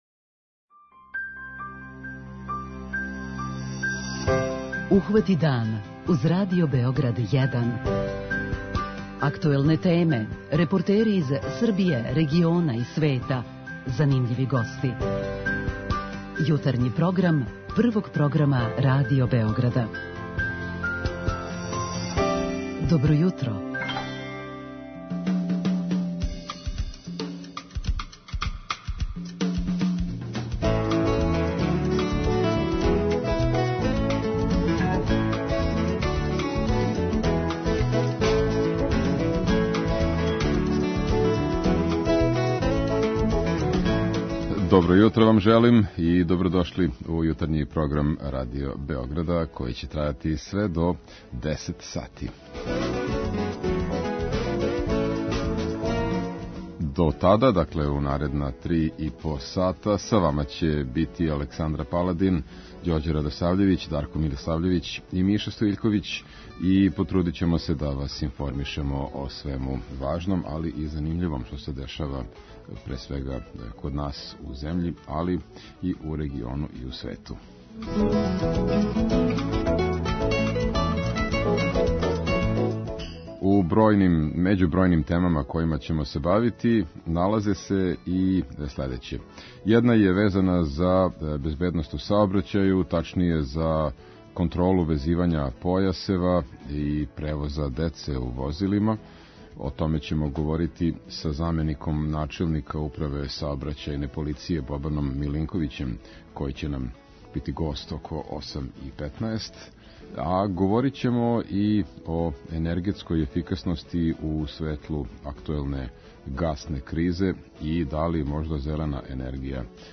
Седми април обележава се као Светски вегетаријански дан па ћемо тим поводом наше слушаоце питати на чему се заснива здрава исхрана и колико они обраћају пажњу на то.